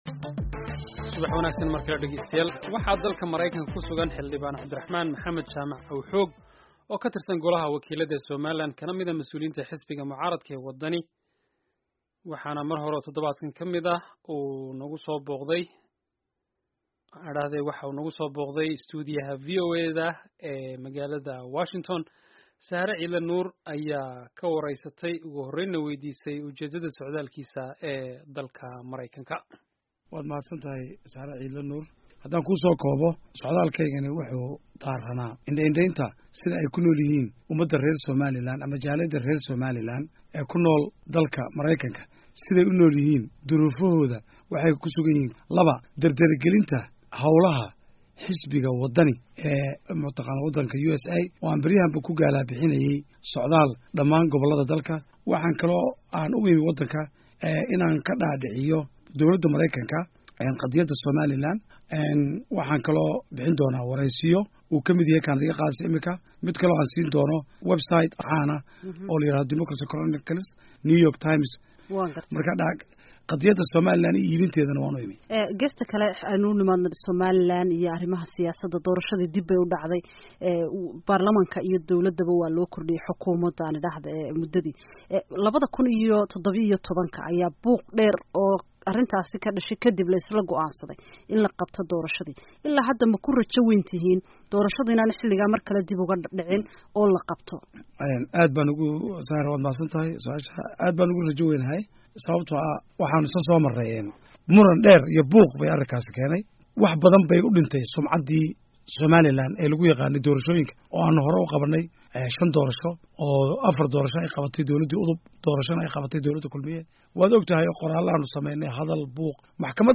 Wareysi: Xildhibaan Aw-xoog